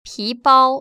[píbāo] 피빠오  ▶